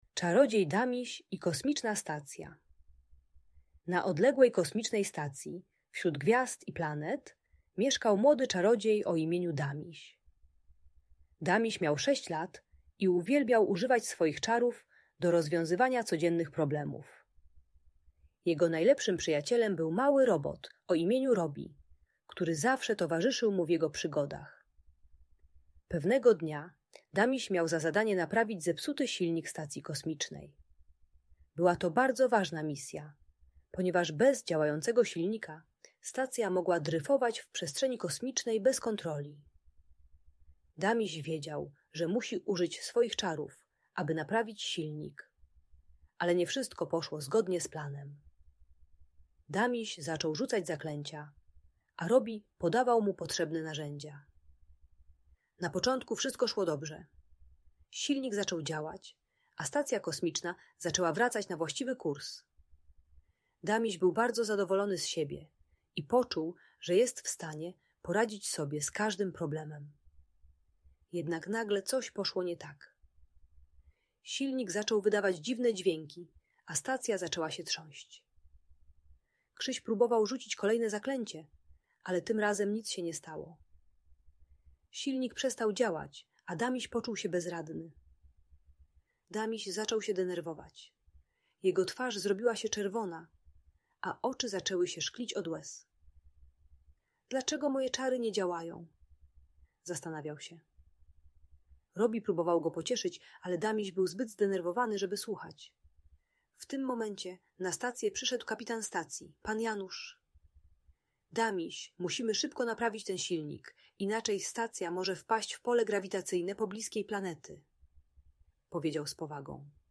Czarodziej Damiś - Audiobajka